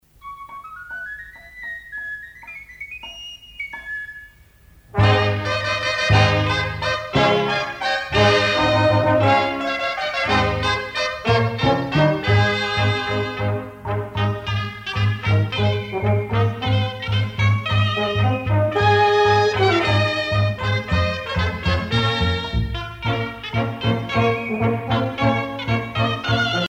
danse : sardane
Pièce musicale éditée